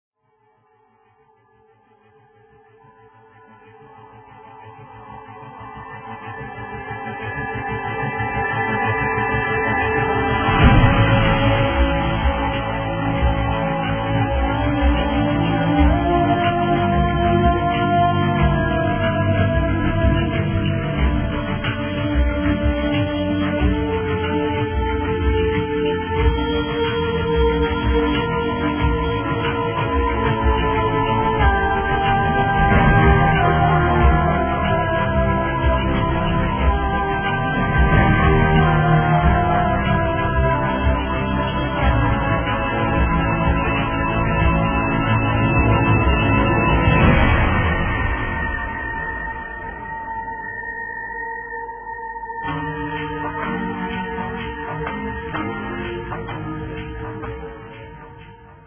Music clip 1, 0'58" (57Kb)